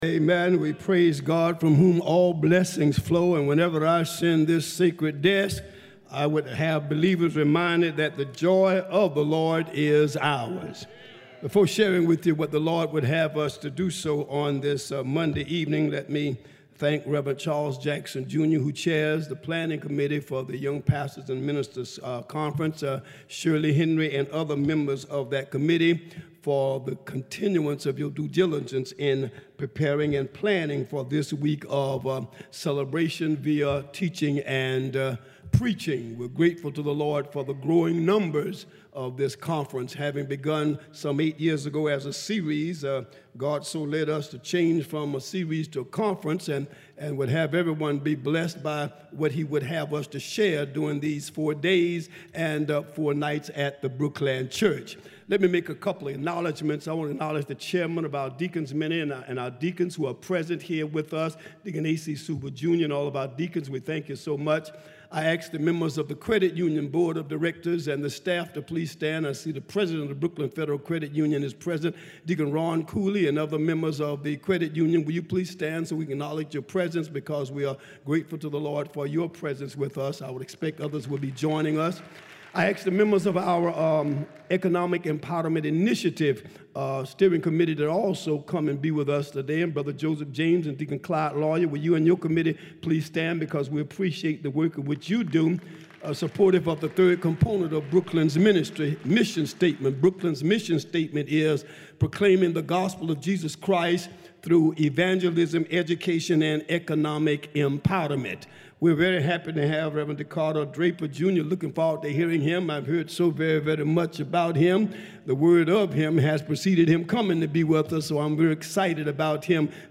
Sermon at the 8th annual Young Pastor’s Conference